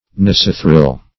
Search Result for " nosethril" : The Collaborative International Dictionary of English v.0.48: Nosethirl \Nose"thirl\, Nosethril \Nose"thril\, n. Nostril.
nosethril.mp3